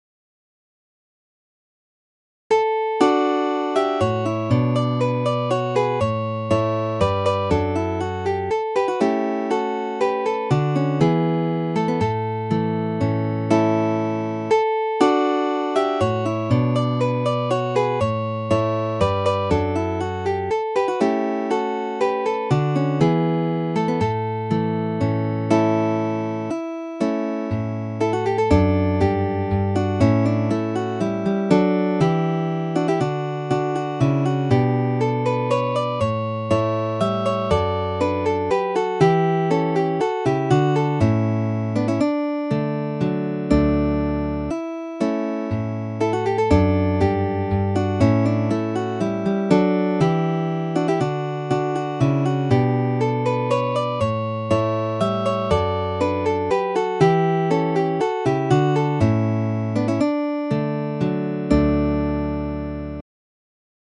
Midi音楽が聴けます 3 1 1 1 90円